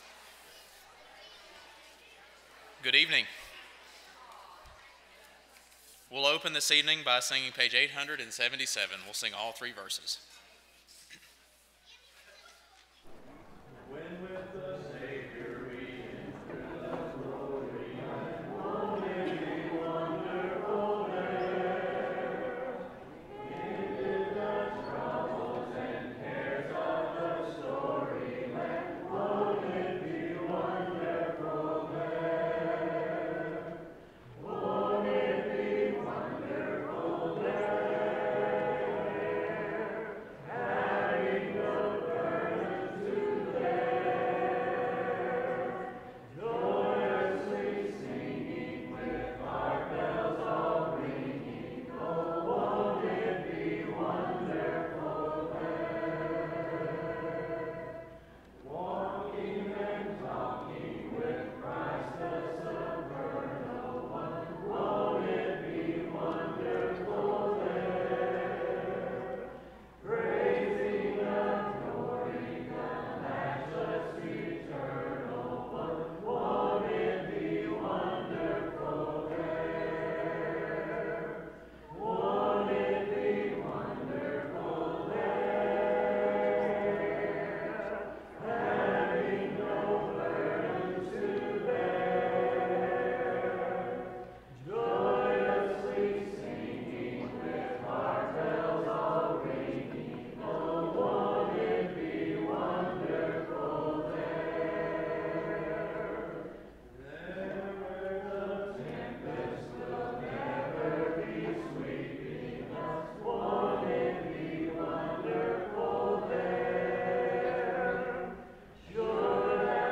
Galatians 4:16, English Standard Version Series: Sunday PM Service